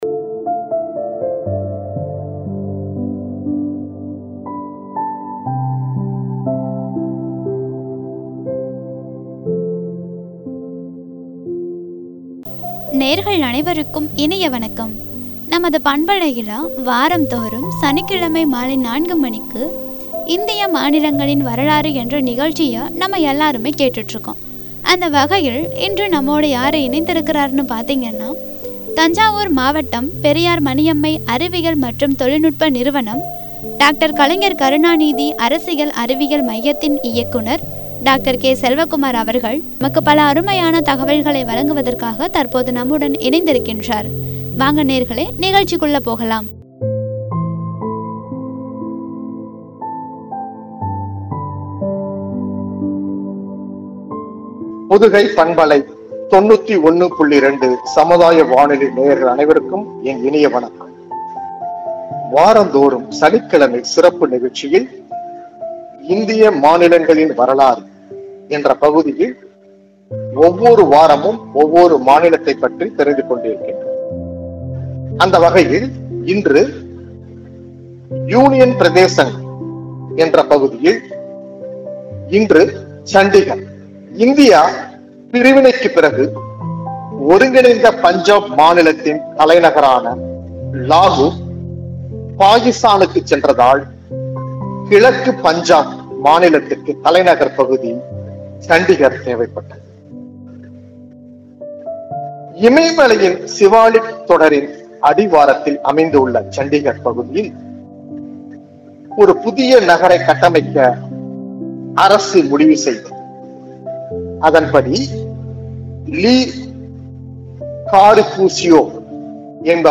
வழங்கிய உரை.